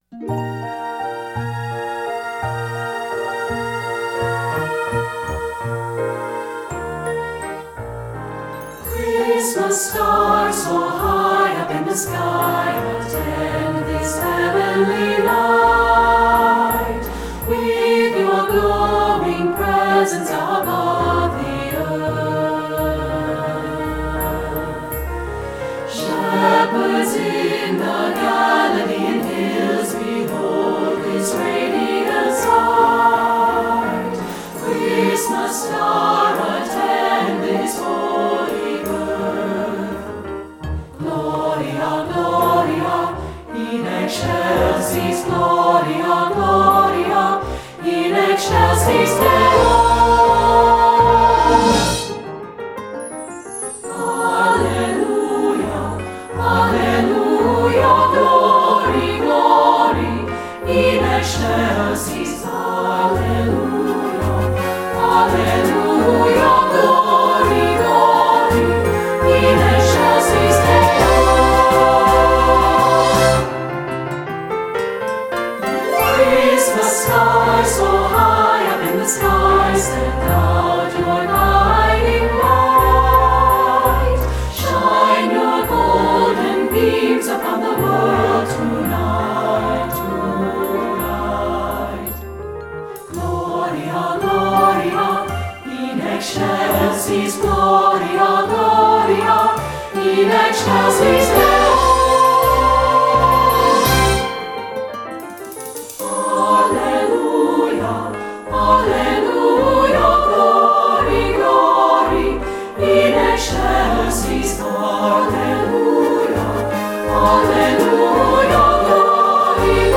secular choral
SSA (SAB recording)